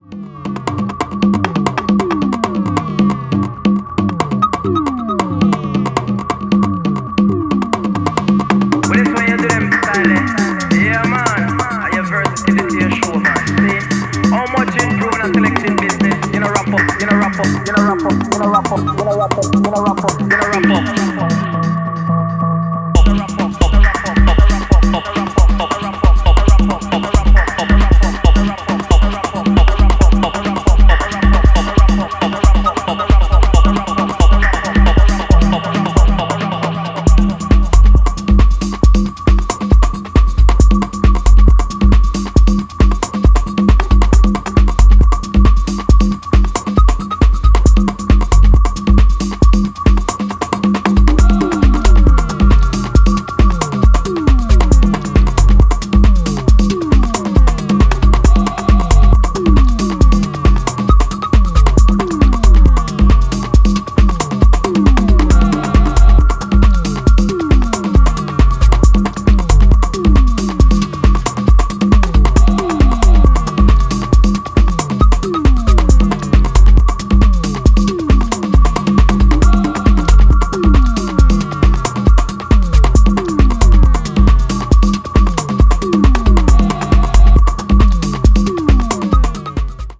伝統的ダブ・テクノの感覚を現代的なスタイルに落とし込んだ感もあり、ジャンルを跨いだフロアで作用してくれるはず。